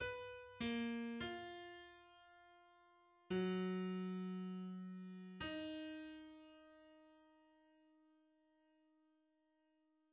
This snippet has some weird notes!